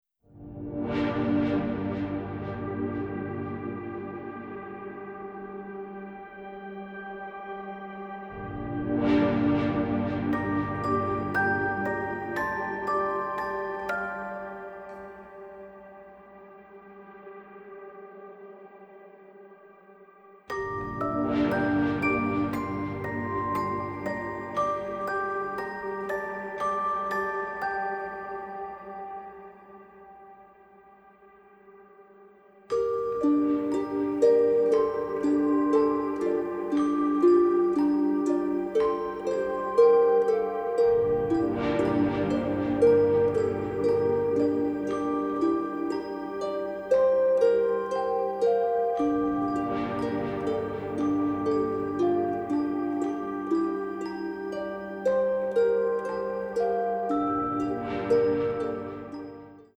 orchestra and chorus